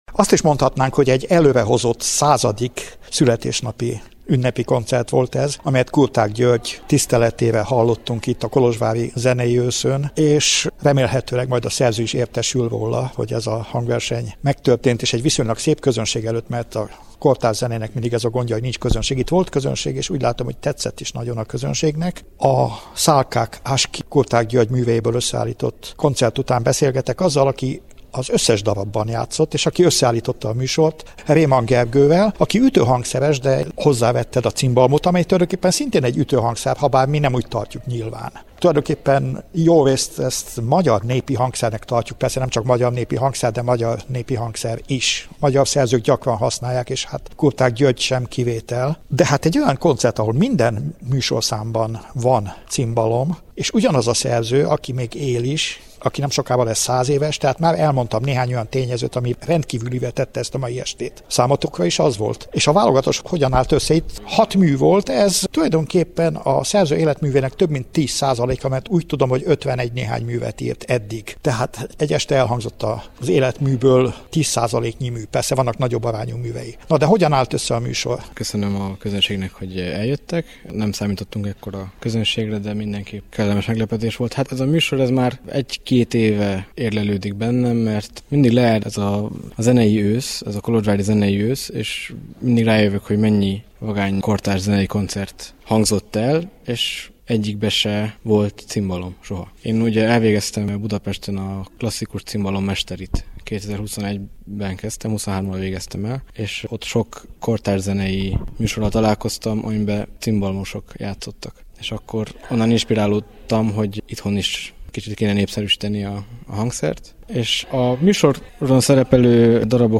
Összeállításunkban beszélgetést hallhatnak az est három előadójával